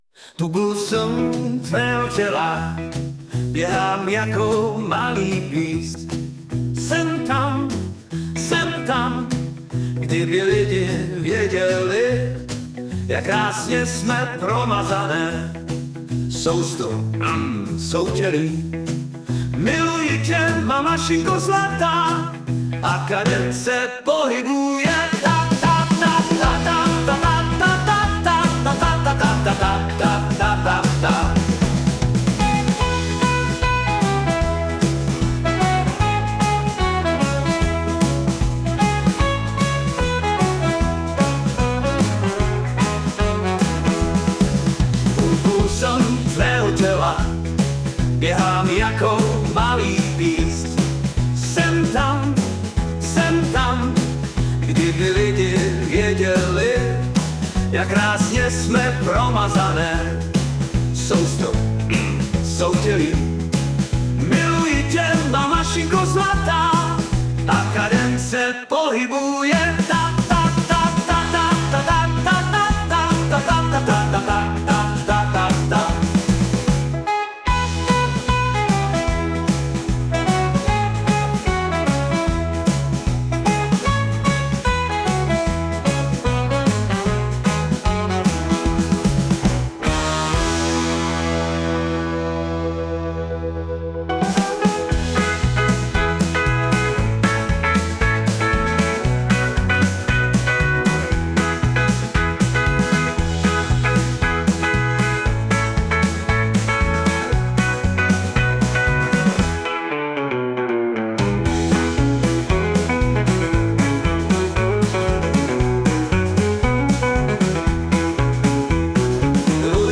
* hudba, zpěv: AI
Alespoň jednu jsem doplnil o hudební doprovod.
Už je ta rychlost vyjádřena i hudebně, tak snad nezklamu. :D